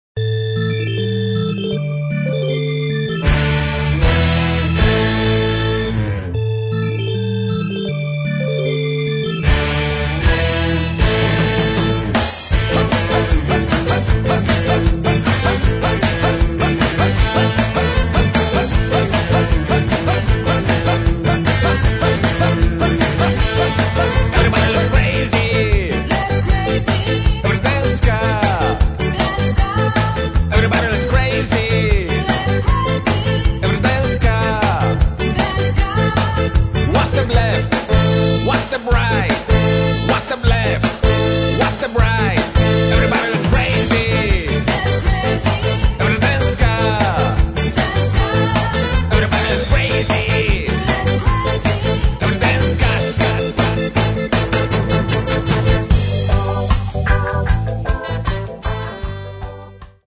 To słoneczne ska